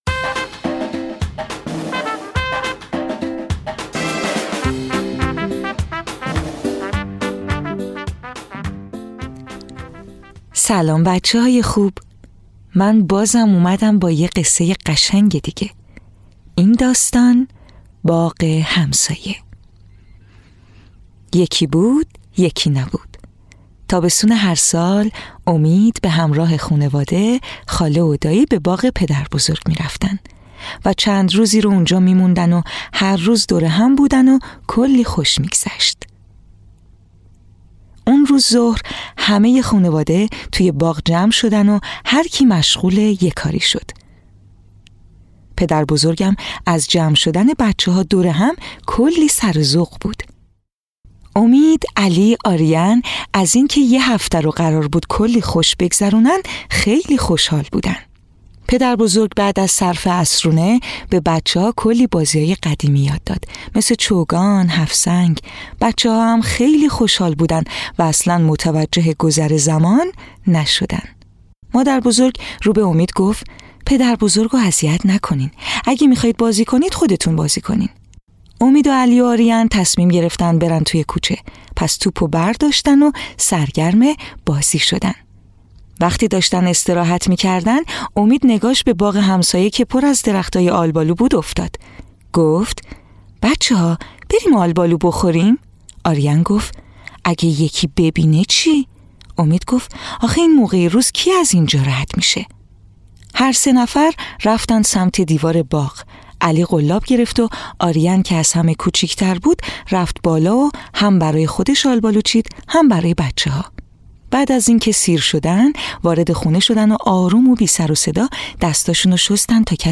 قصه‌های کودکانه صوتی - این داستان: باغ همسایه
تهیه شده در استودیو نت به نت